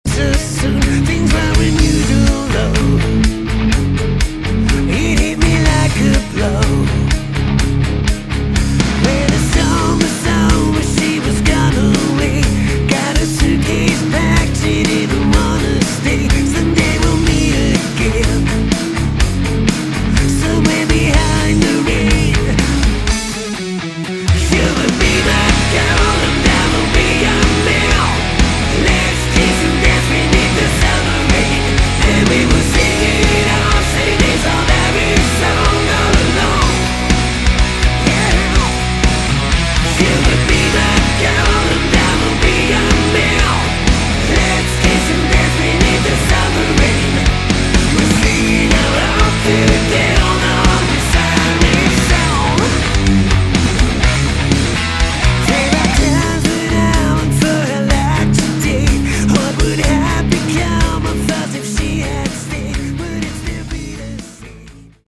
Category: Hard Rock
Vocals
Guitar
Bass
Drums